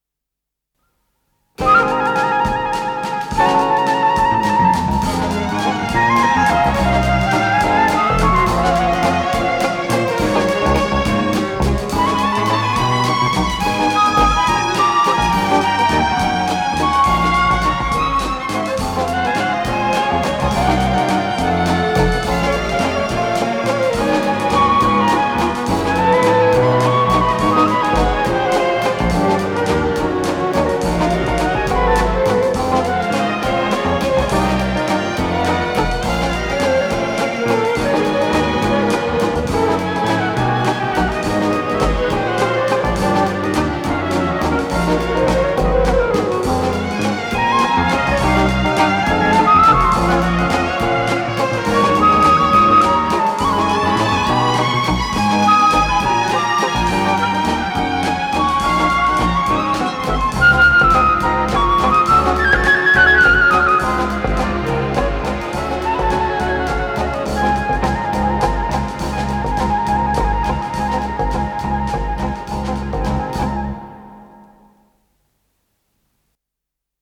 с профессиональной магнитной ленты
ПодзаголовокЗаставка, ре бемоль мажор
ВариантДубль моно